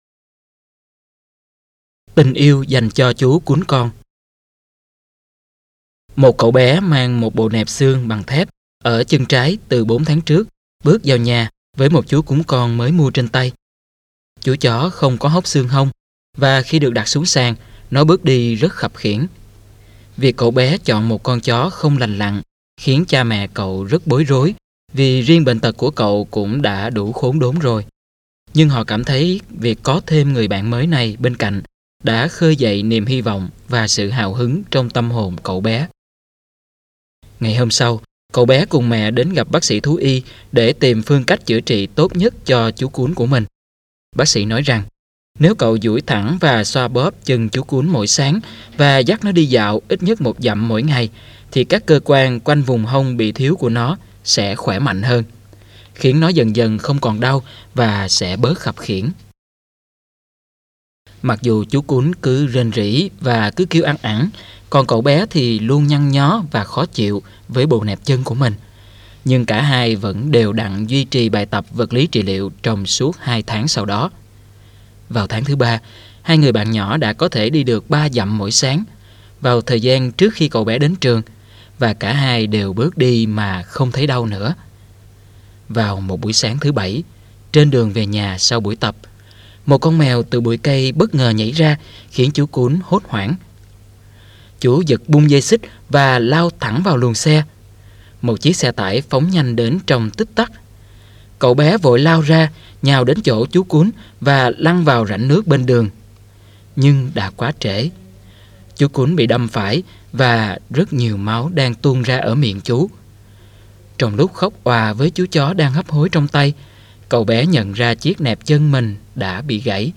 Sách nói | Tình yêu dành cho chú Cún con